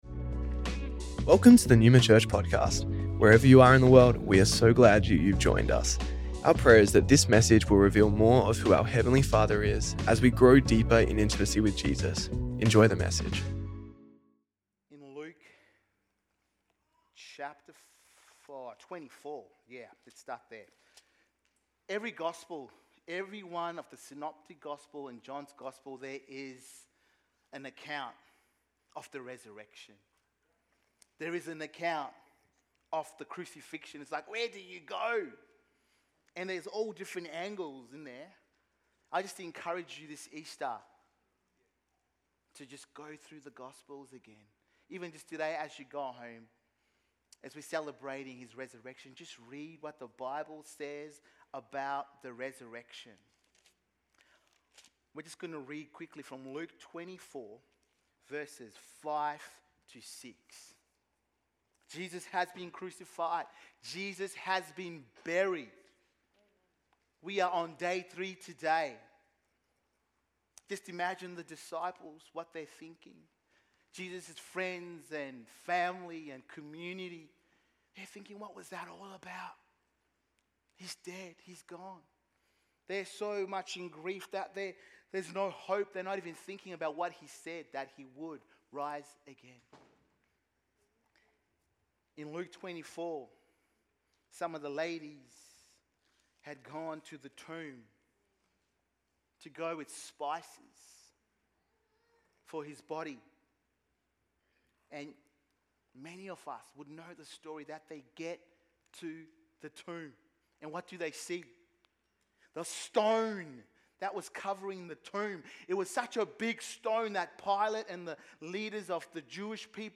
Neuma Church Melbourne South Originally recorded at the 10AM Service on Sunday 20th April 2025